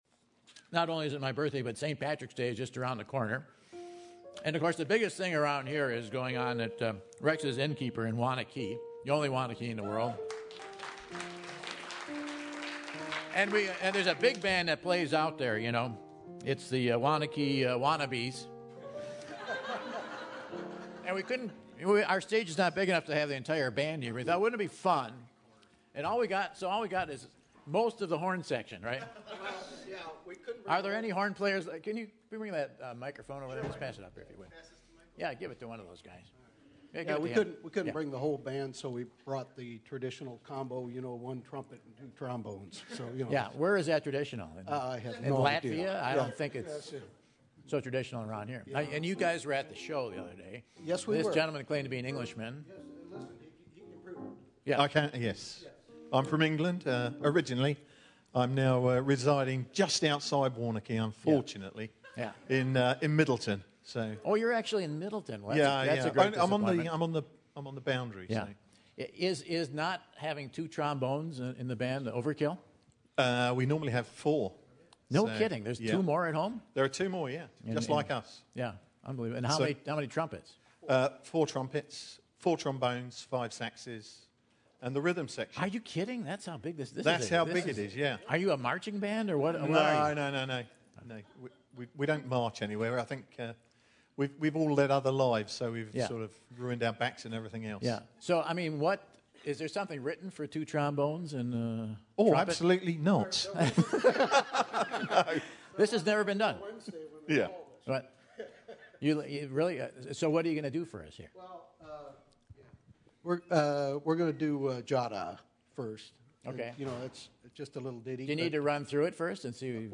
As a component of the Waunakee Community Band, the WCB Big Band is also all volunteer.